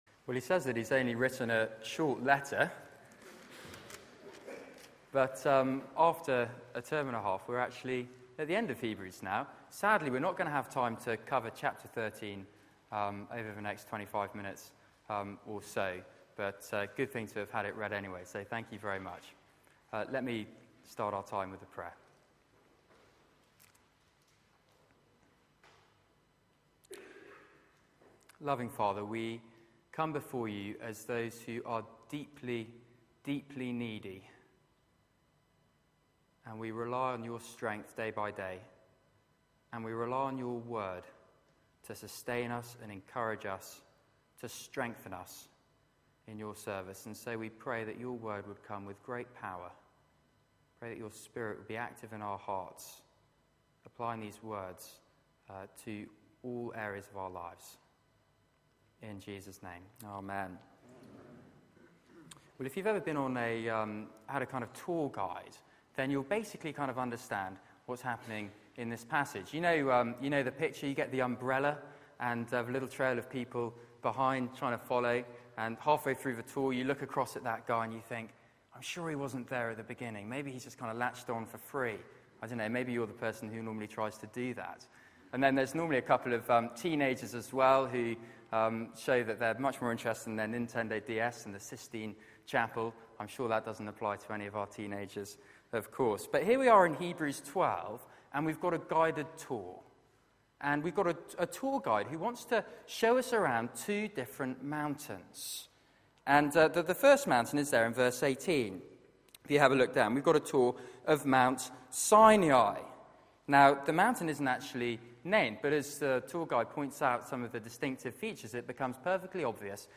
Media for 6:30pm Service
Passage: Hebrews 12:18-13:25 Series: Jesus is better Theme: True worship Sermon